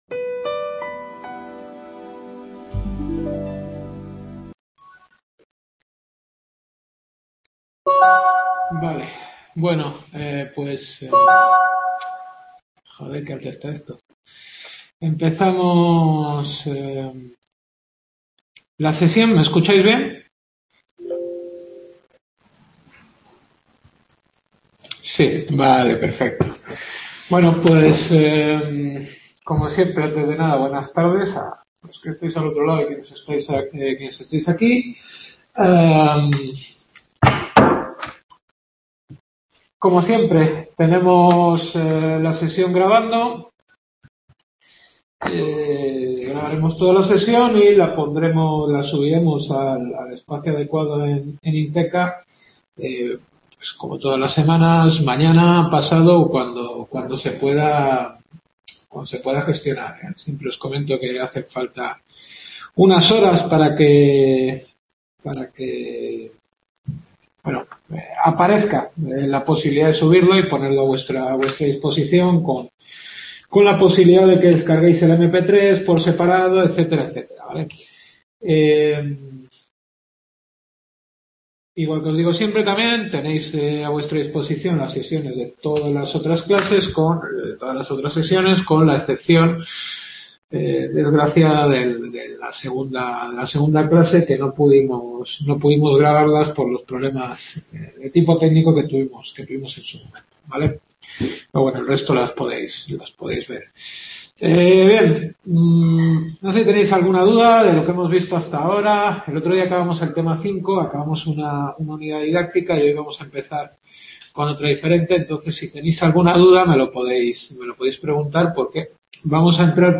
Filosofía del Derecho. Sexta clase.